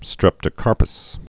(strĕptə-kärpəs)